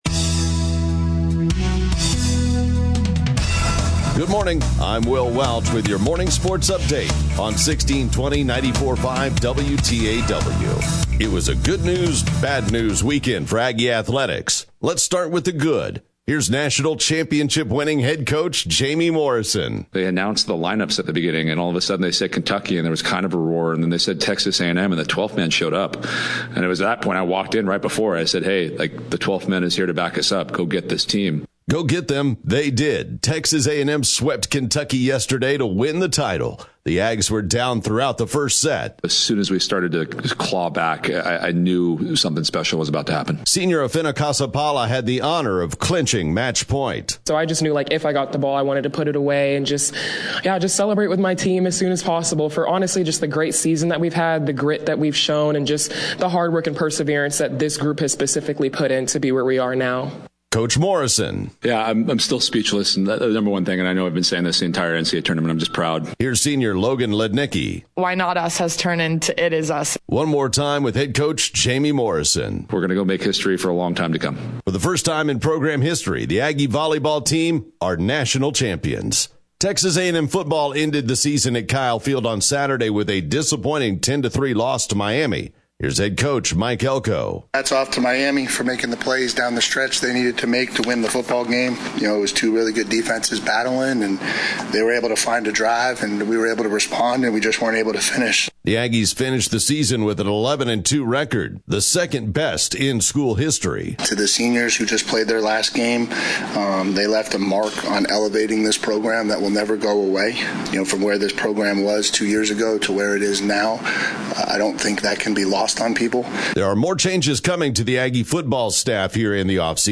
TBNA 2025 Radio Sportscast